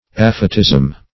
Aphetism \Aph"e*tism\, n.